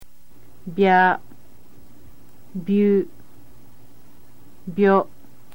bya.mp3